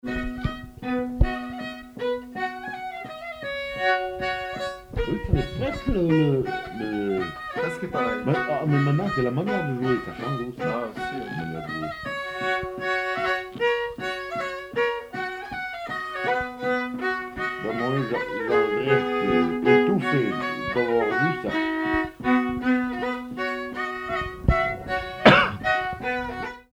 danse : mazurka
circonstance : bal, dancerie
Pièce musicale inédite